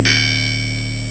3_crusty-lo-hat.wav